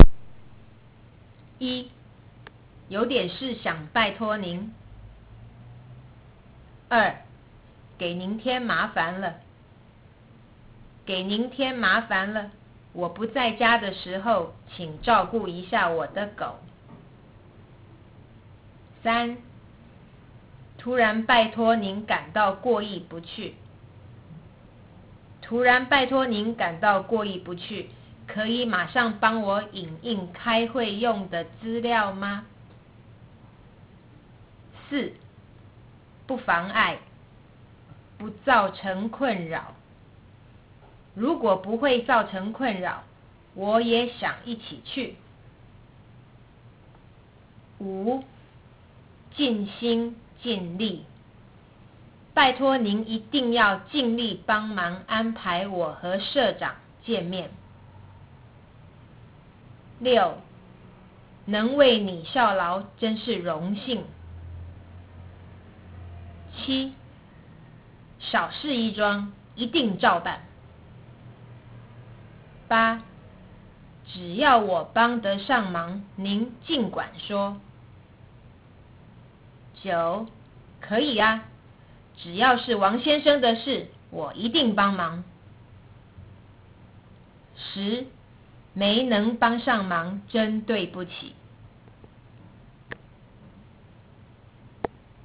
これらの発音を聞いてみましょう　→